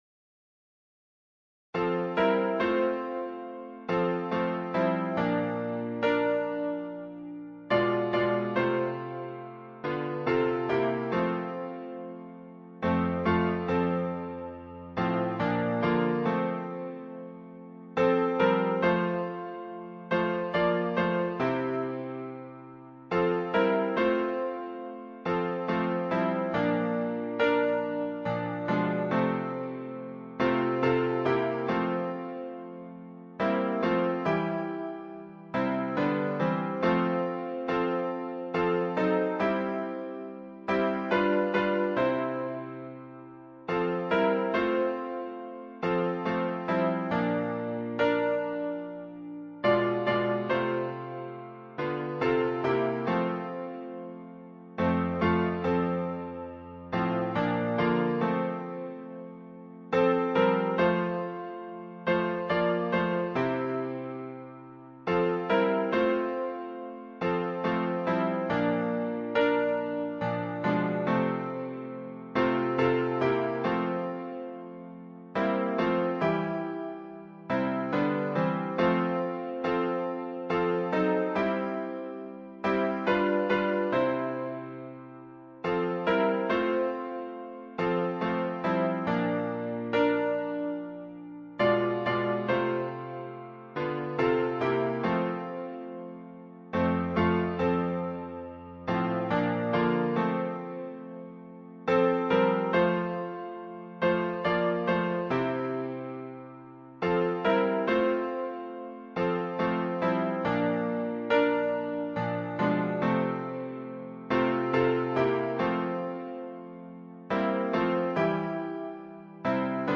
伴奏